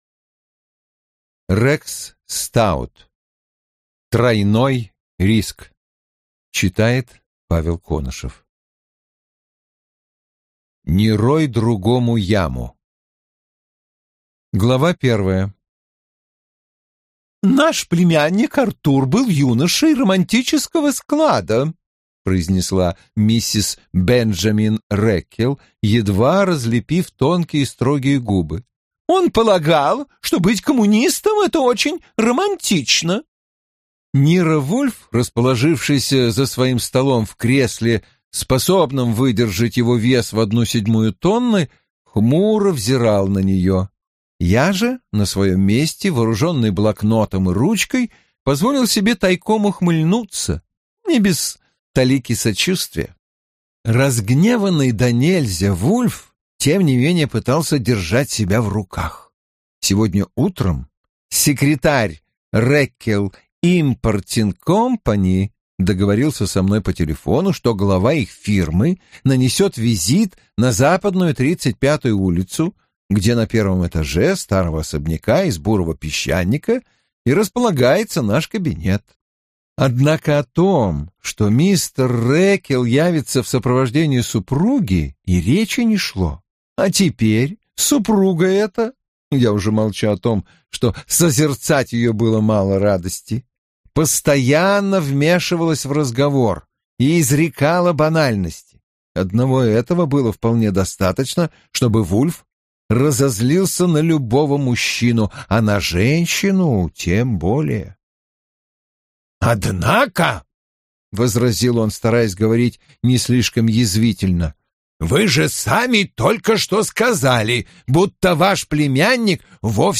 Аудиокнига Тройной риск | Библиотека аудиокниг